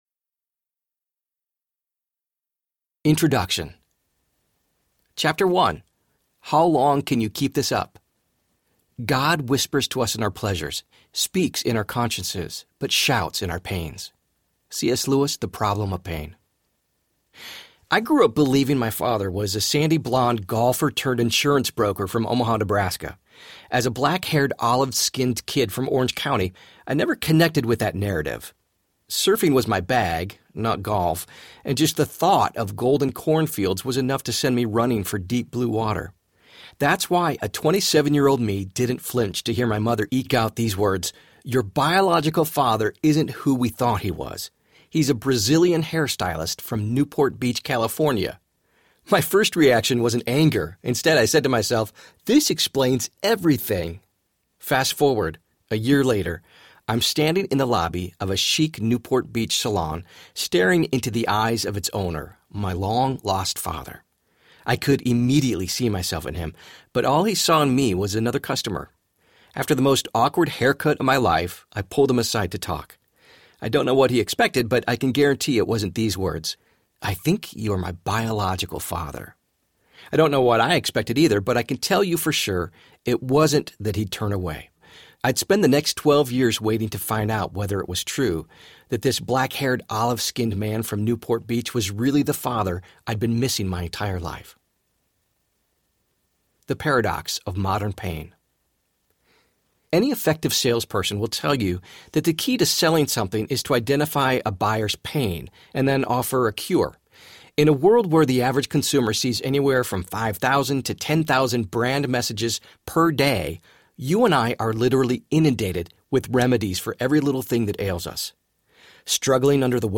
How to Be Present in an Absent World Audiobook
Narrator
7.4 Hrs. – Unabridged